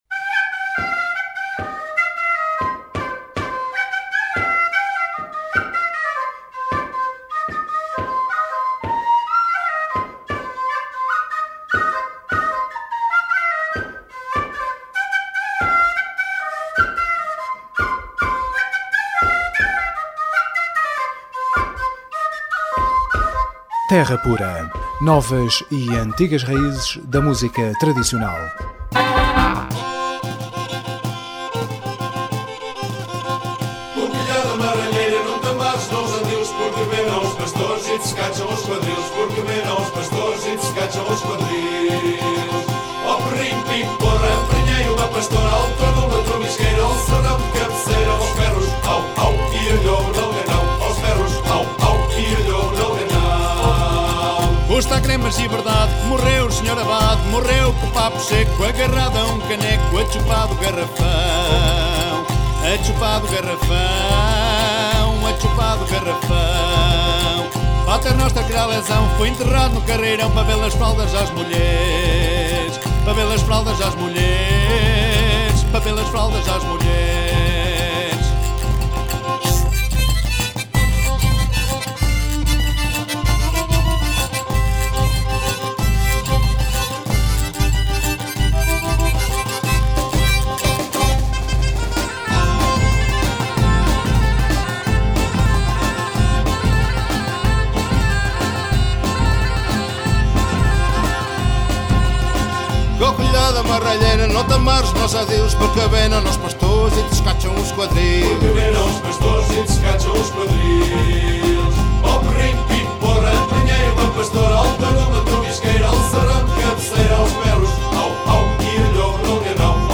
Terra Pura 18AGO10: Entrevista Galandum Galundaina